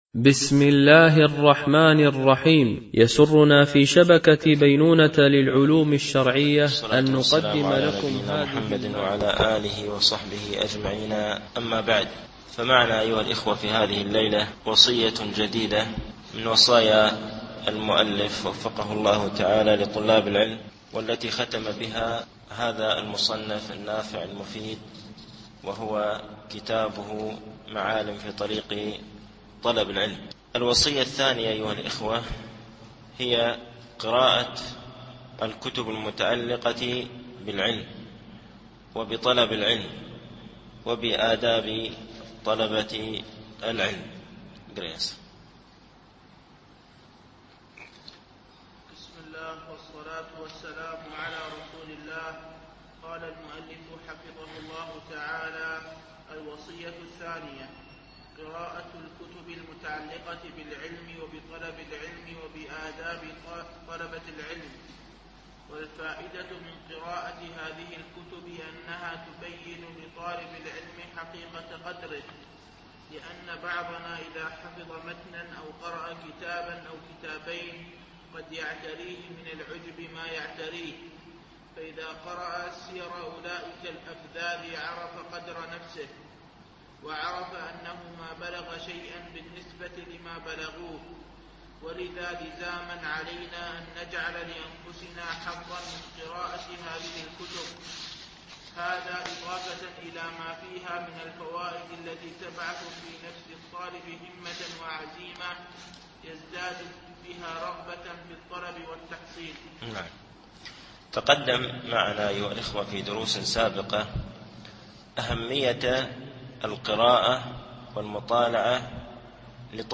التعليق على كتاب معالم في طريق طلب العلم (وصية2و3أهمية مطالعة الكتب الخاصة بطلب العلم) - الدرس الأربعون